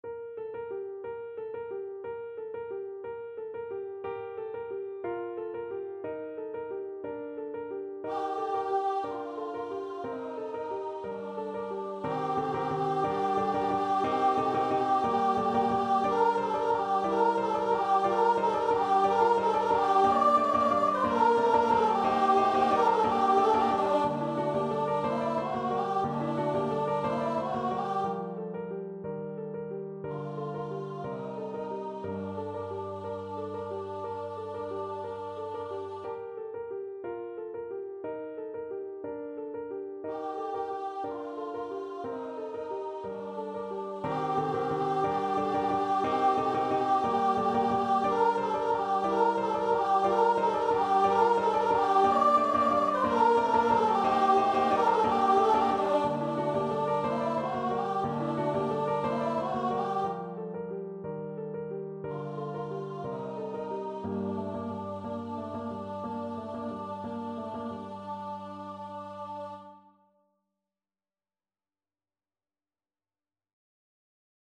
Free Sheet music for Choir (SATB)
3/4 (View more 3/4 Music)